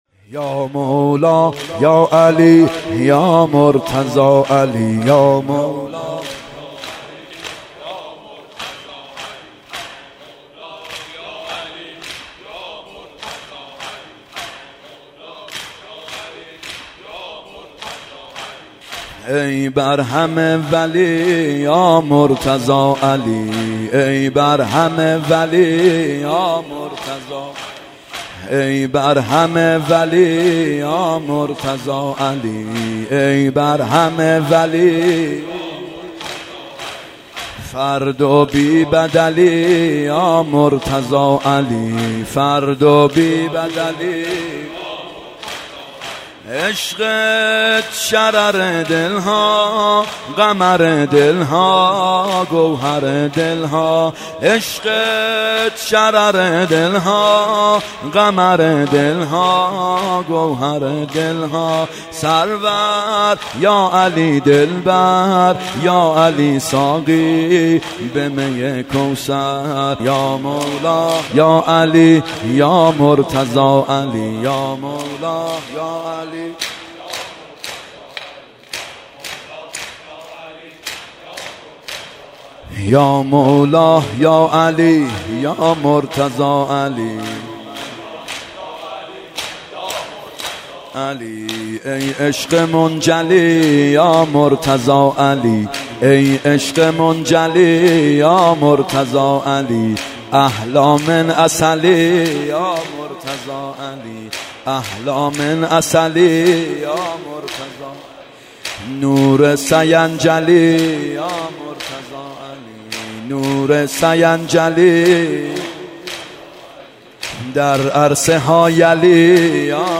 شب قدر 92 واحد تند
شب قدر 92